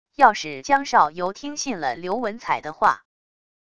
要是江少游听信了刘文采的话wav音频生成系统WAV Audio Player